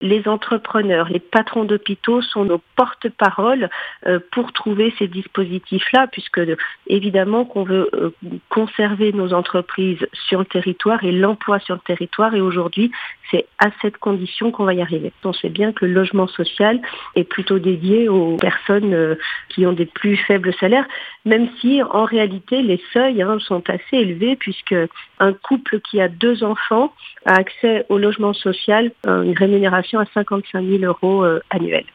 Véronique Riotton, Députée Renaissance de Haute-Savoie.